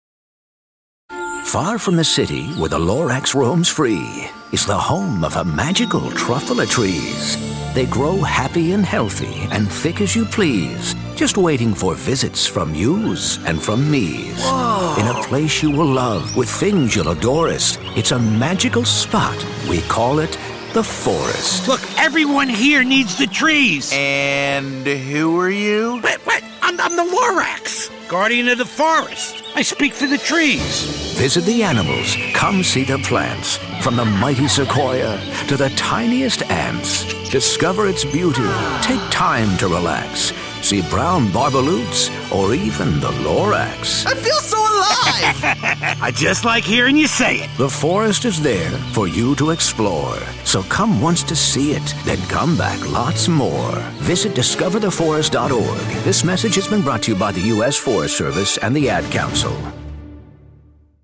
David Kaye - The Lorax - PSA